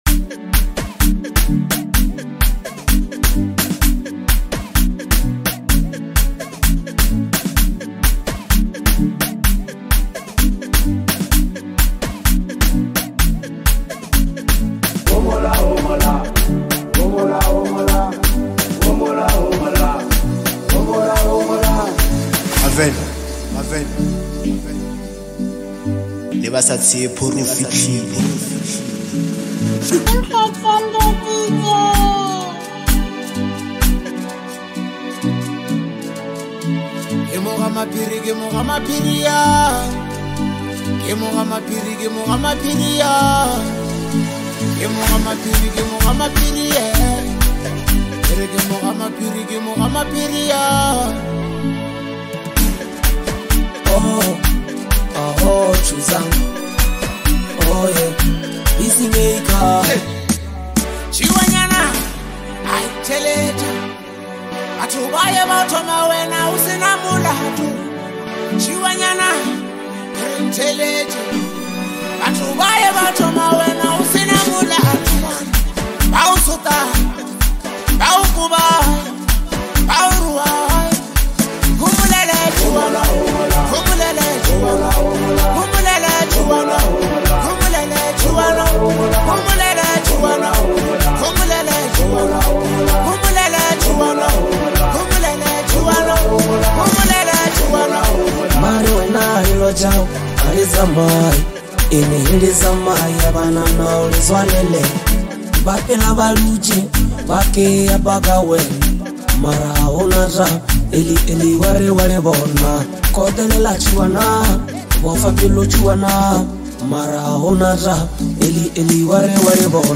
electrifying energy and infectious rhythm
powerful and emotive vocals